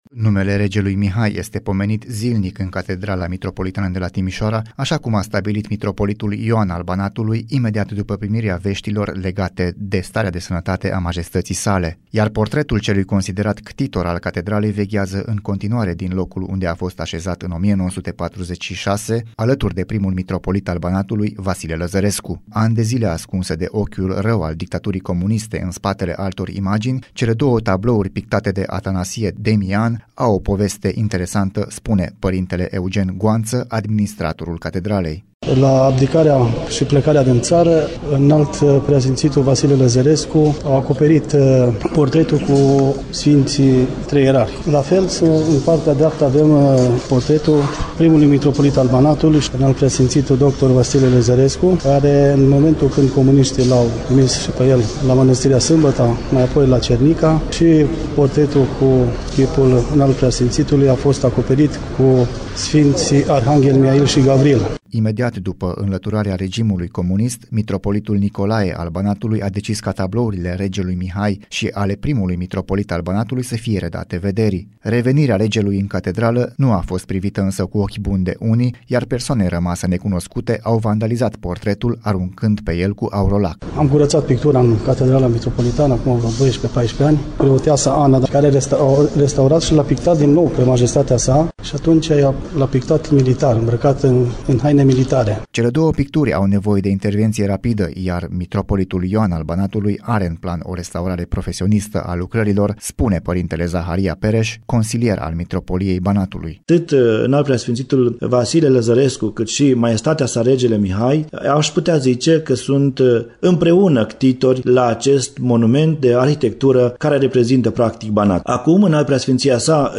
REPORTAJ / Cum a fost ascuns portretul Regelui Mihai din Catedrala Mitropolitană din Timişoara chiar sub ochii comuniştilor